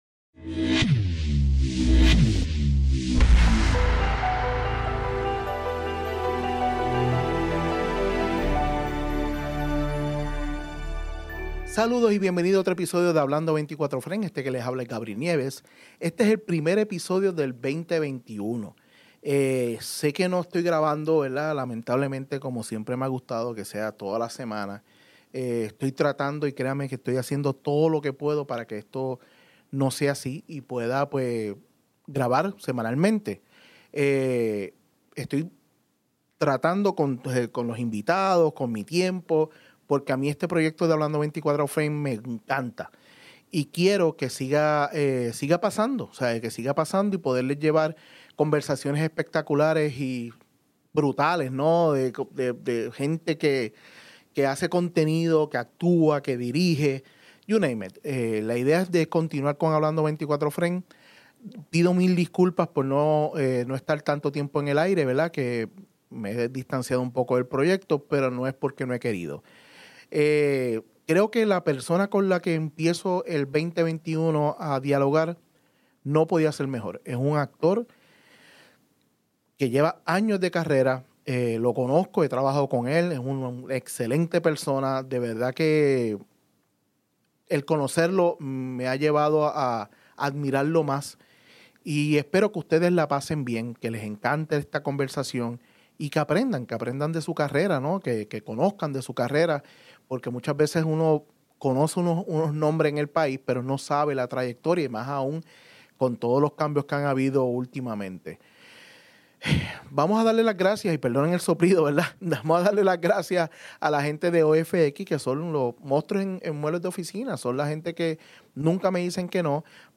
Nuestro primer invitado de HA24F es el gran actor Braulio Castillo. Braulio nos hable de su carrera y de como a evolucionado el medio de las comunicaciones. Acompañame en esta mega conversación.